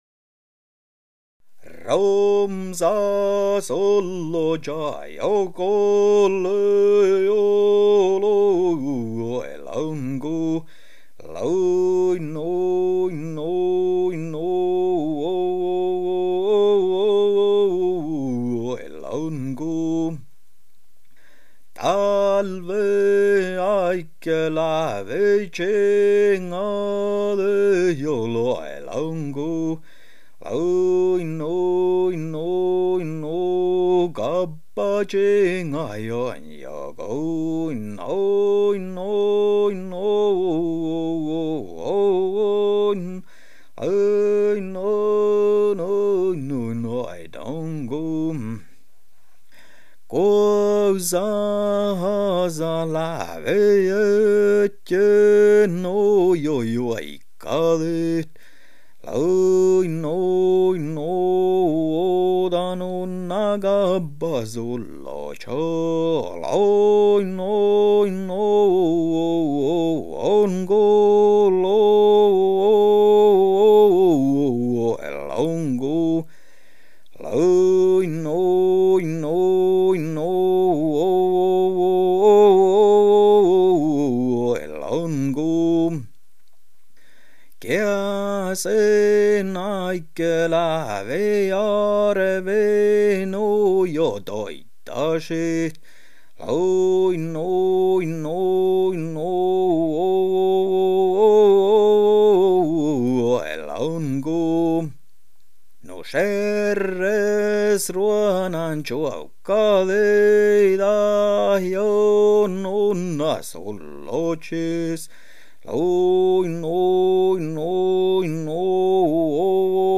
Finally Some yoiks